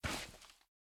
Minecraft Version Minecraft Version snapshot Latest Release | Latest Snapshot snapshot / assets / minecraft / sounds / mob / turtle / walk5.ogg Compare With Compare With Latest Release | Latest Snapshot
walk5.ogg